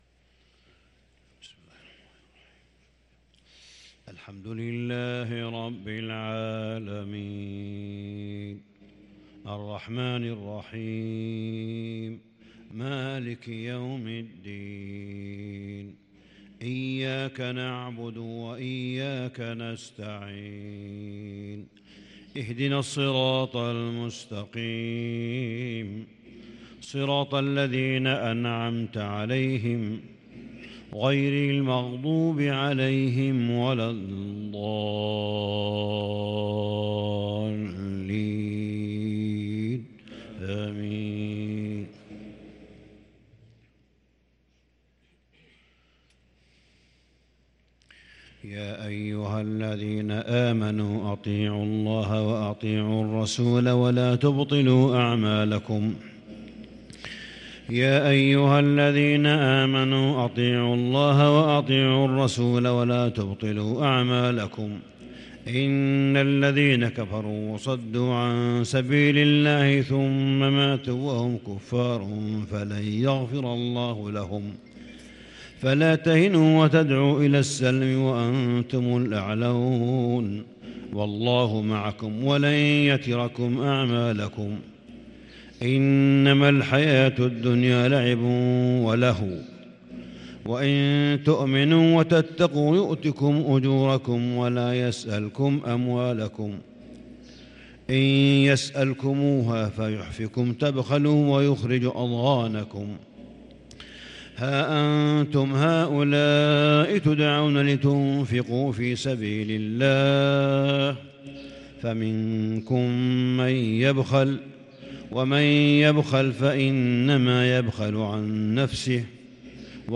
صلاة الفجر 1 شوال 1443هـ | من سورتي محمد والفتح | Fajr prayer from Surat Muhammad and Al-Fath 2-5-2022 > 1443 🕋 > الفروض - تلاوات الحرمين